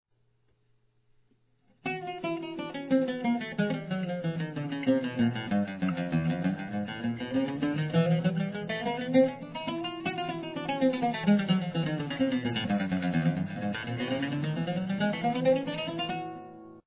It is a chromatic exercise that is tough for both hands after a while.  You start with your little finger (4) on F# on the D string and work your way down chromatically to the bass E string, and back up again, over and over.
CHROMATIC EXAMPLE - 2 PASSES
chromatic1.mp3